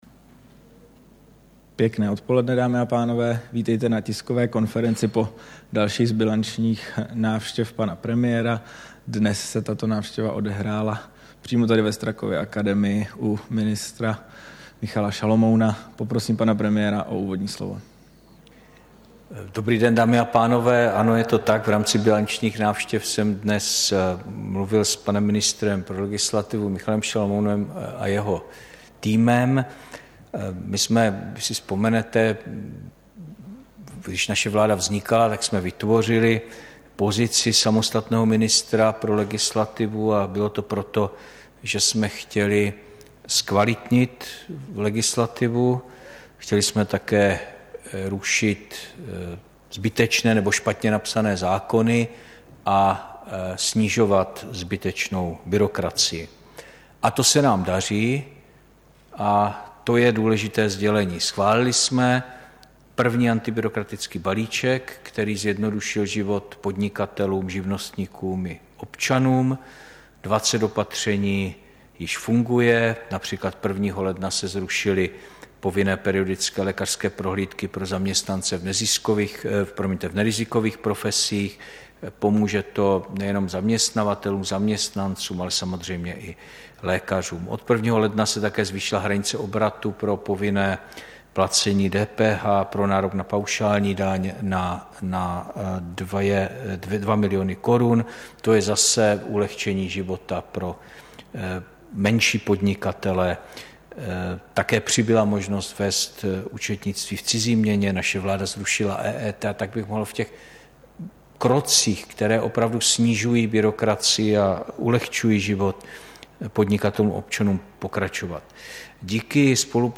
Tisková konference po bilanční návštěvě premiéra Petra Fialy u ministra pro legislativu Michala Šalomouna, 13. dubna 2023